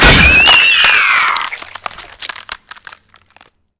snap.wav